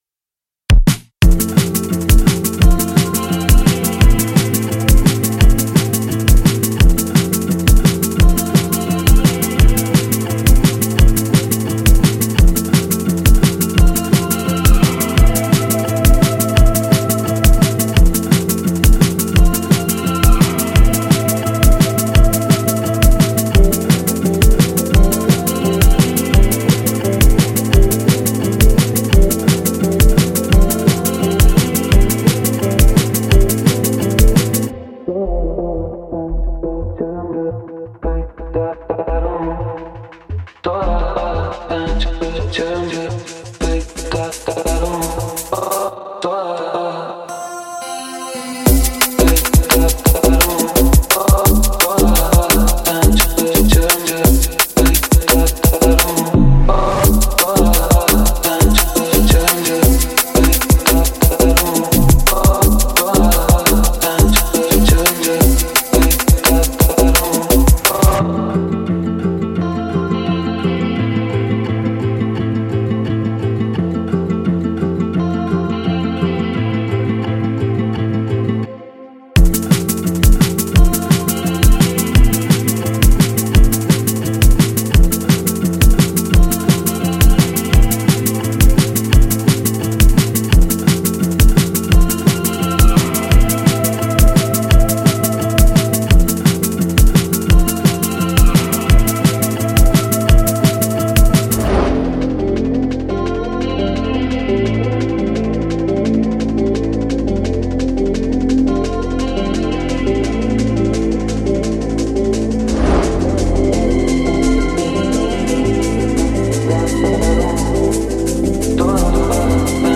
رپ غمگین